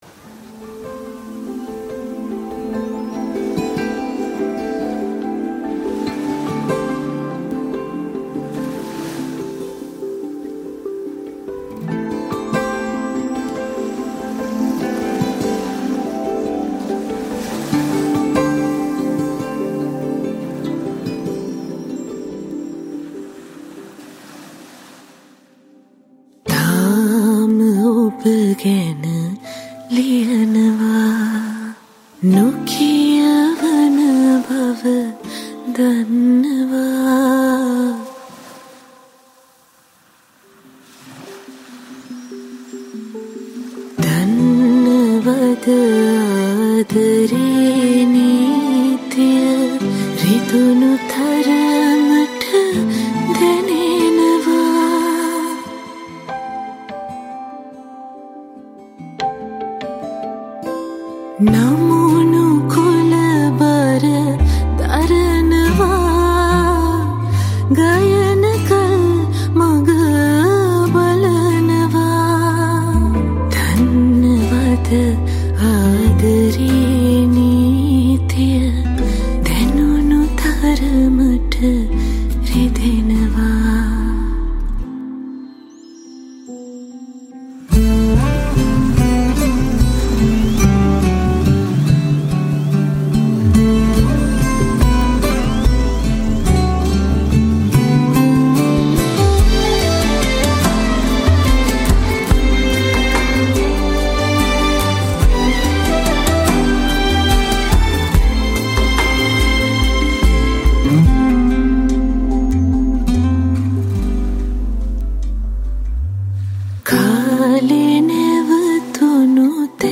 Guitars
Violin | Viola